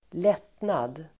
Uttal: [²l'et:nad]